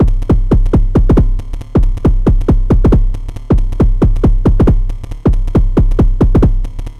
• kick and perc analog techno volcadrum 7 - 137 Gm.wav